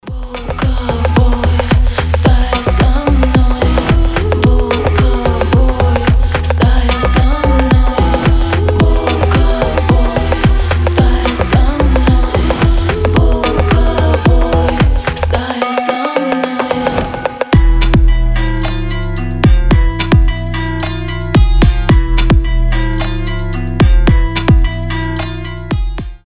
• Качество: 320, Stereo
атмосферные
Electronic
вой волка
этнические
Нетипичное звучание для поп песни